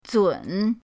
zun3.mp3